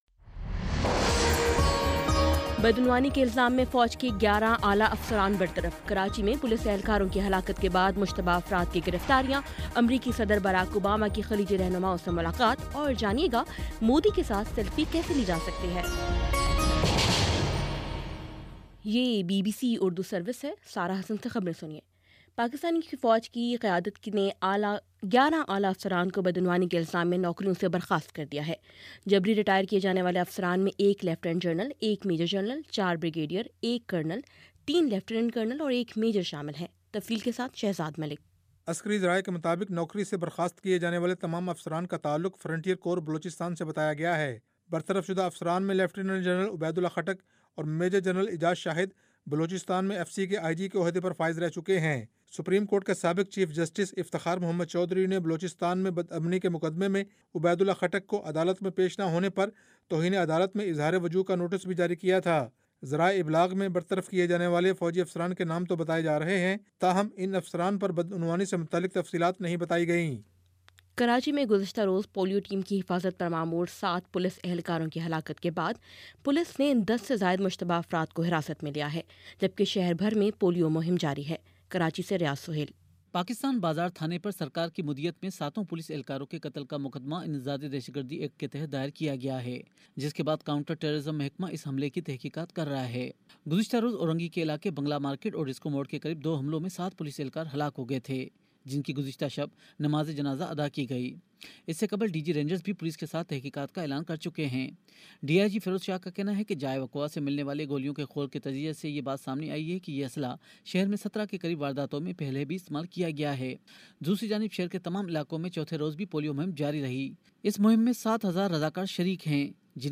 اپریل 21 : شام پانچ بجے کا نیوز بُلیٹن